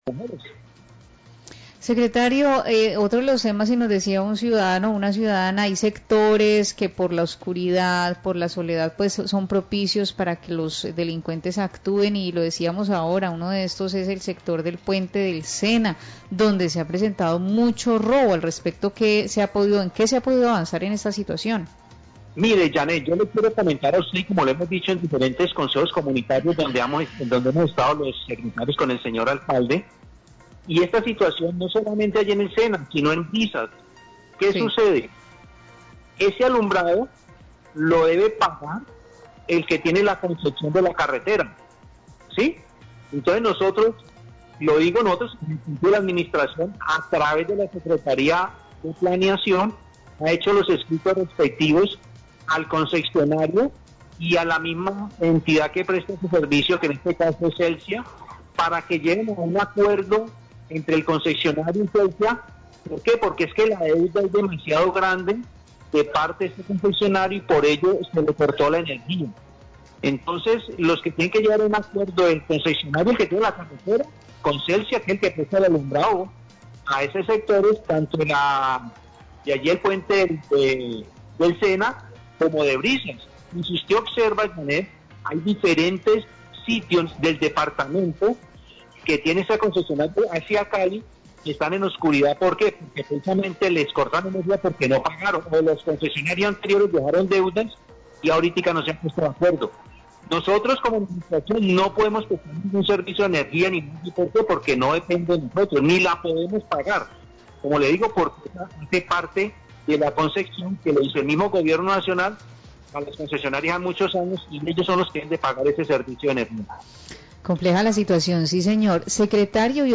Secretario de Gobierno Buga explica porqué no funciona alumbrado público en puente del Sena, Voces de Occidente 1210pm
Radio